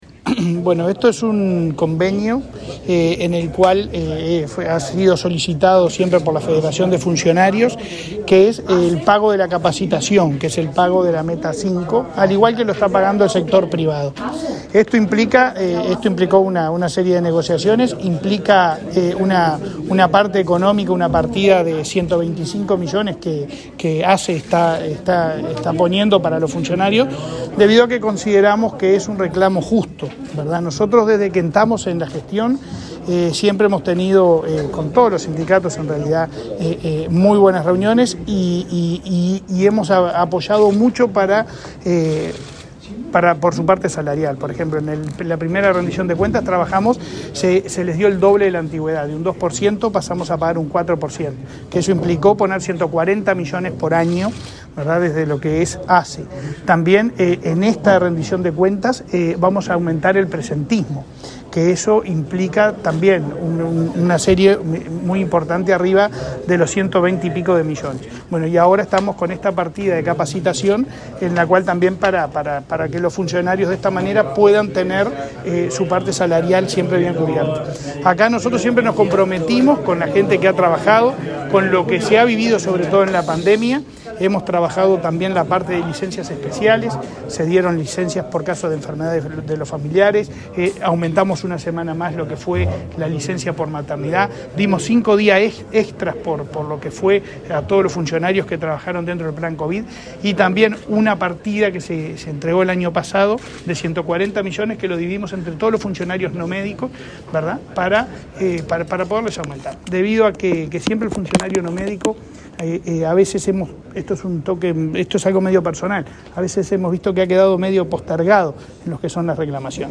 Declaraciones del presidente de ASSE, Leonardo Cipriani